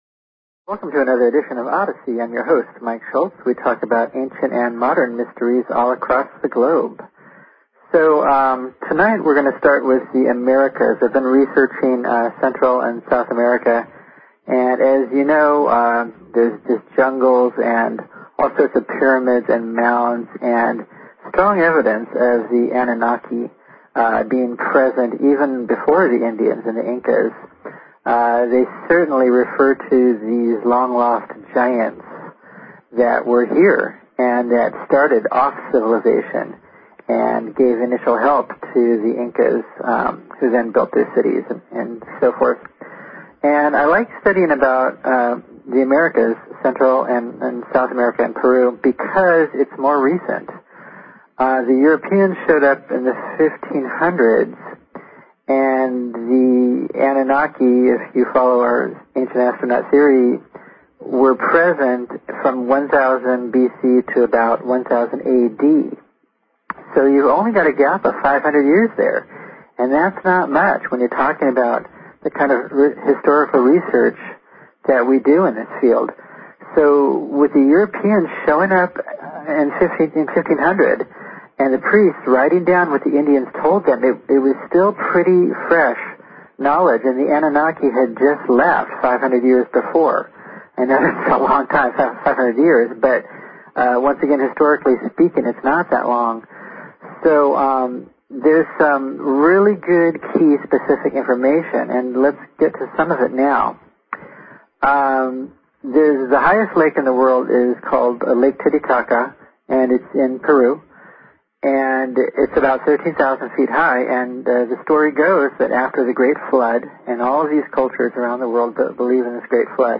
Talk Show Episode, Audio Podcast, Odyssey and Courtesy of BBS Radio on , show guests , about , categorized as